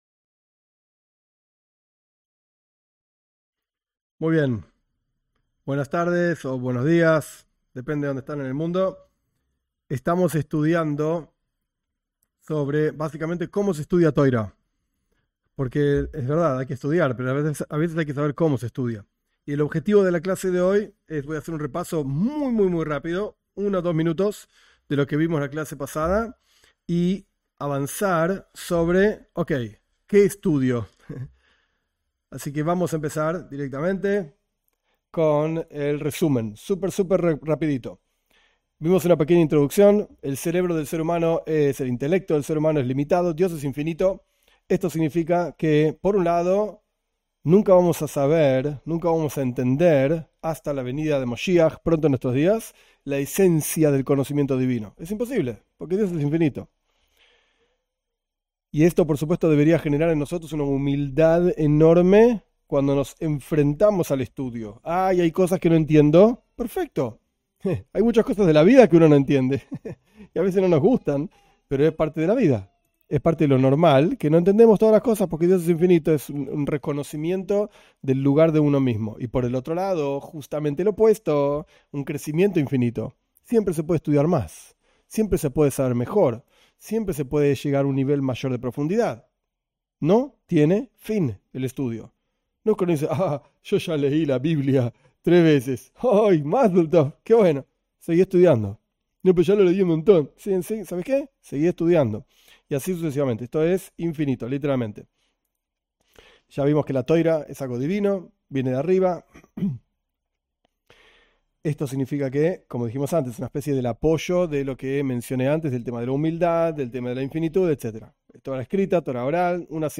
En esta clase se explica cómo se estudia Torá. Desde el comienzo hasta la profundización y se explican los libros básicos (y avanzados) que pueden ayudar a abordar el estudio.